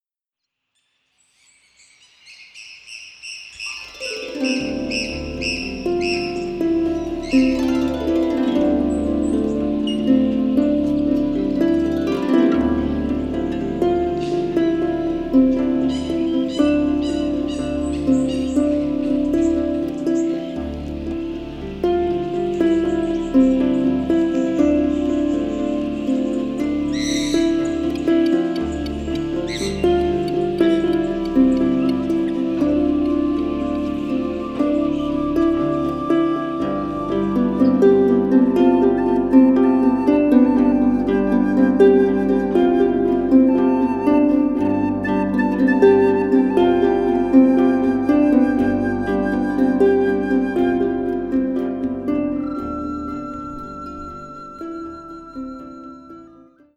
Recorded at the Royal Botanic gardens